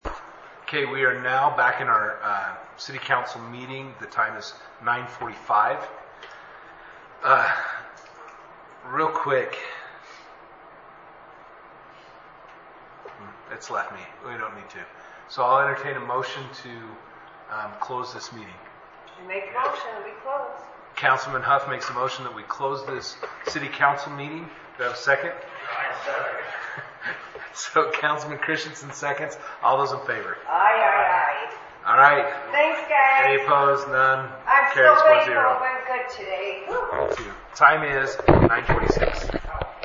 City Council Meeting